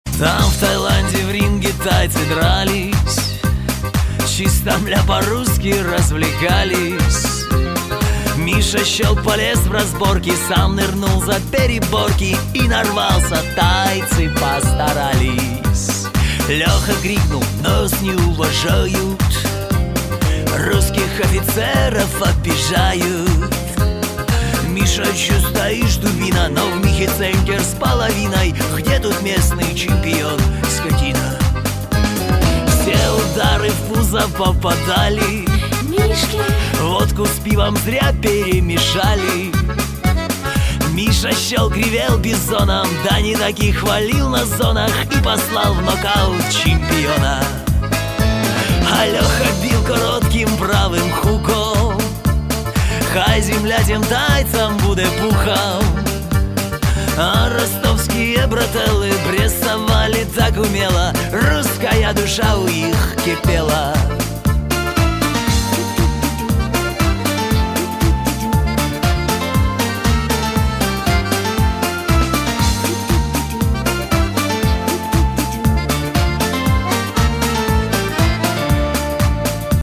• Качество: 128, Stereo
мужской вокал
русский шансон